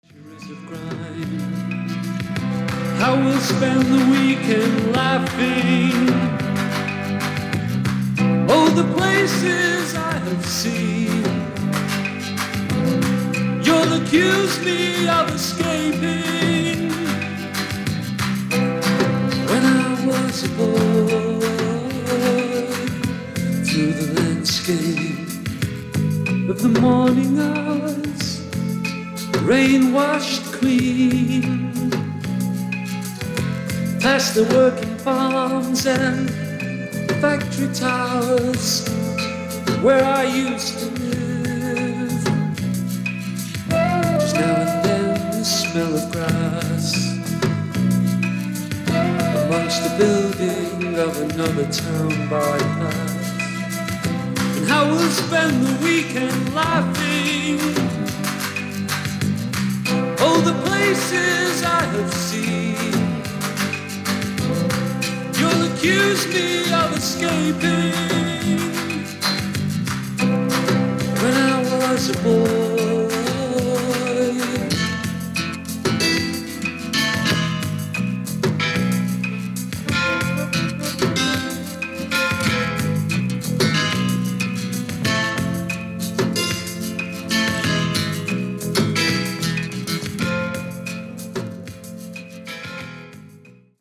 ドラマチックな楽曲でハマっちゃいます！！！